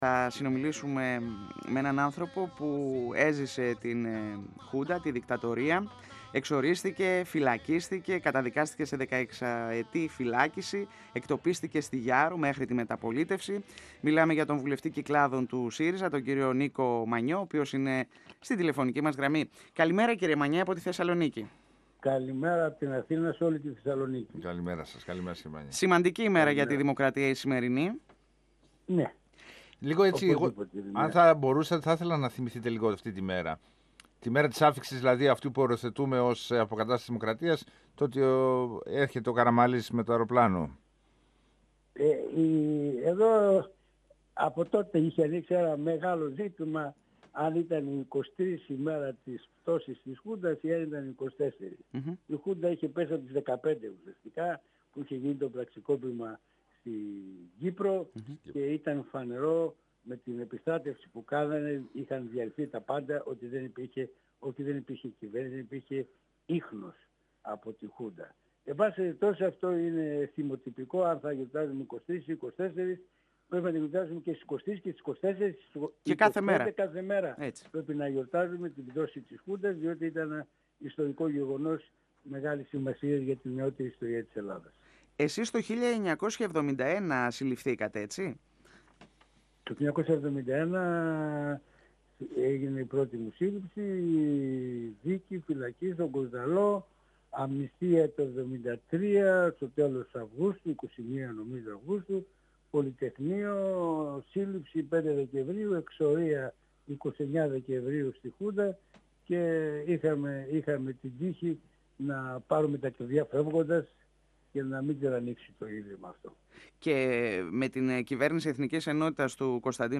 24Ιολ2017 – Ο βουλευτής Κυκλάδων του ΣΥΡΙΖΑ Νίκος Μανιός στον 102 fm της ΕΡΤ3